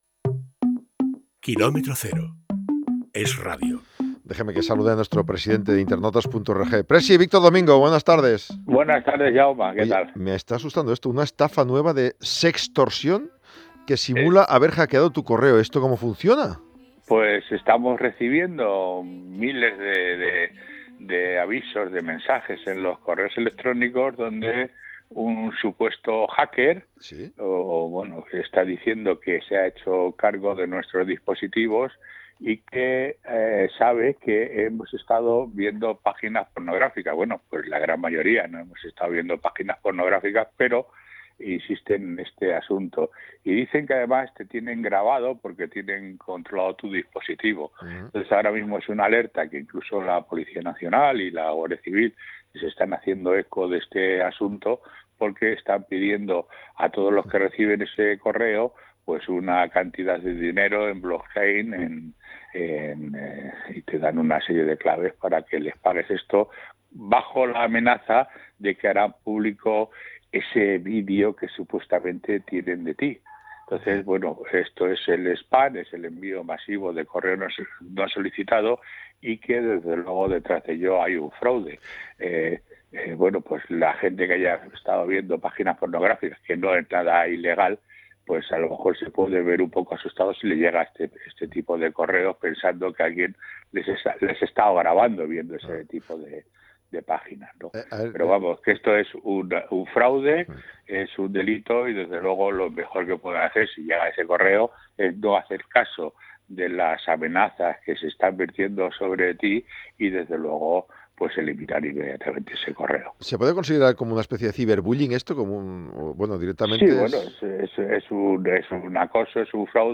conversa con